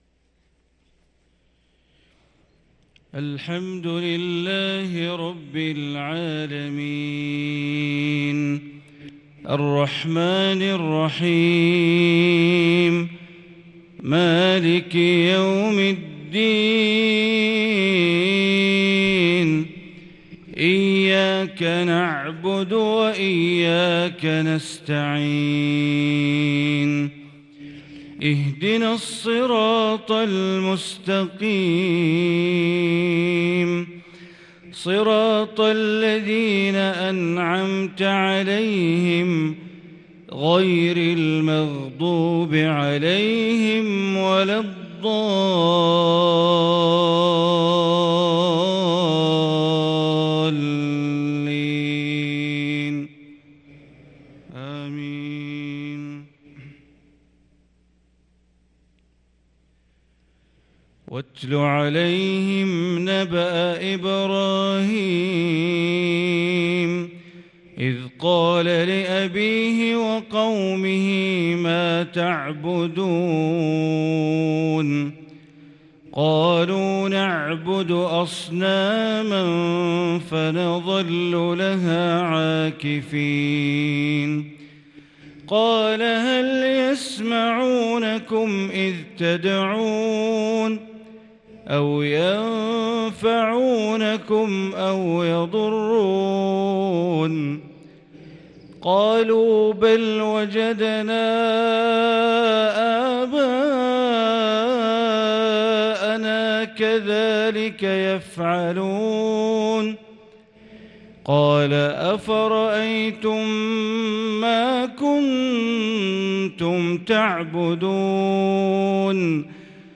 صلاة الفجر للقارئ بندر بليلة 15 ذو الحجة 1443 هـ